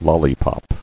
[lol·li·pop]